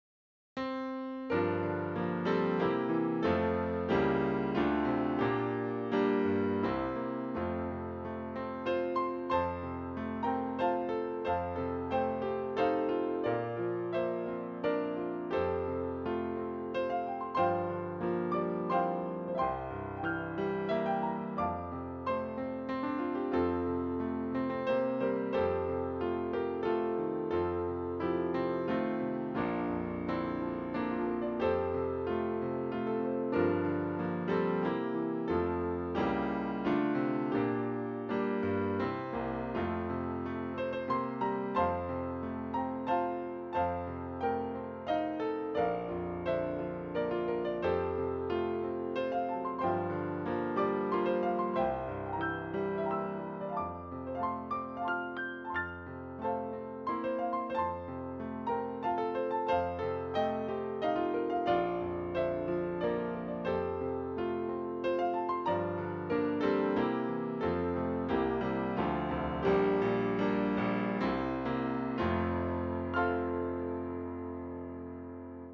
Key: F